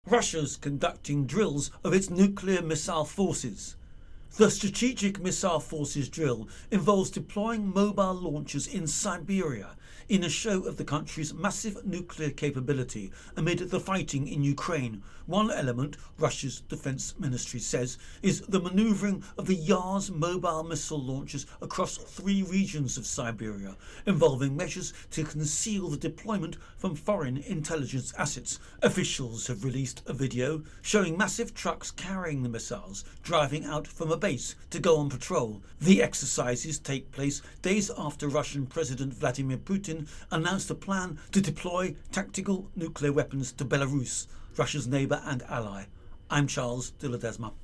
reports on Russia Nuclear Drills